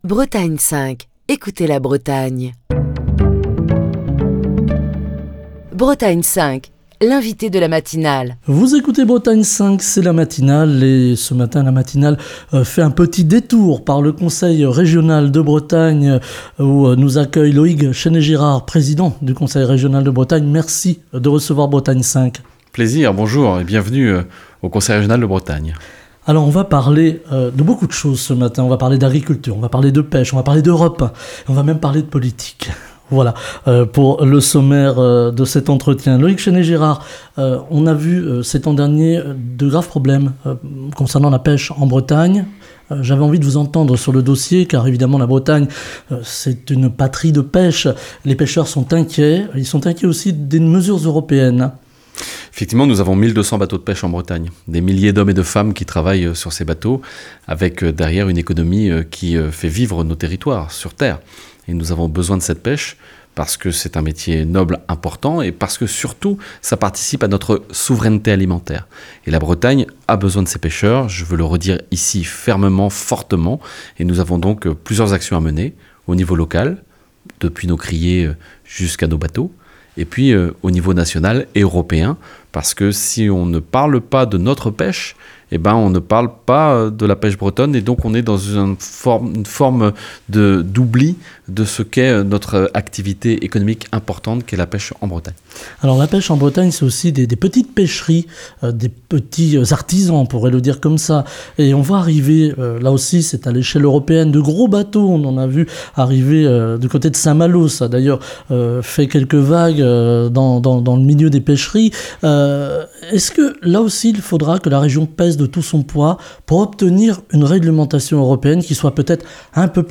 Ce lundi, l'entretien de la matinale n'a pas lieu dans les studios de Bretagne 5, mais au Conseil régional de Bretagne à Rennes où nous reçoit Loïg Chesnais-Girard, président du Conseil régional de Bretagne. Avec lui, nous évoquons les élections européennes du 9 juin prochain et les grands sujets qui concernent directement la Bretagne, comme la pêche, l'agriculture, la souveraineté énergétique et le développement énergies renouvelables en Bretagne avec les parcs éoliens en mer.